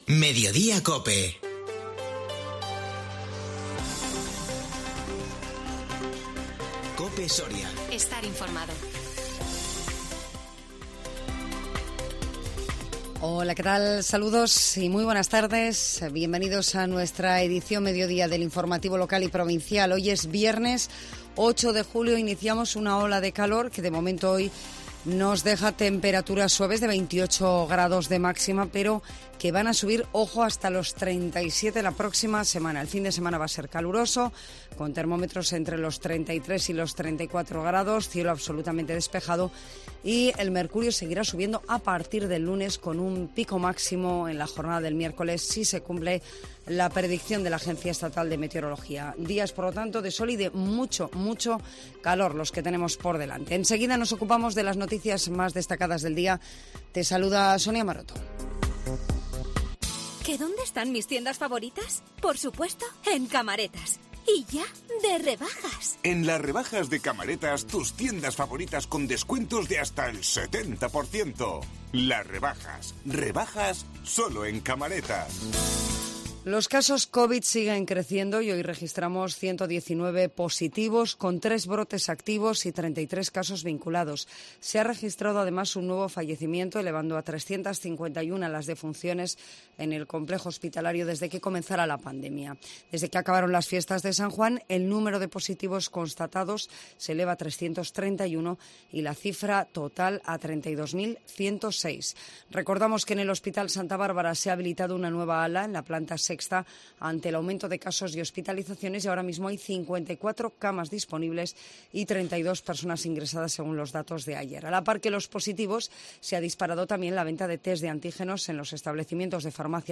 INFORMATIVO MEDIODÍA COPE SORIA 8 JULIO 2022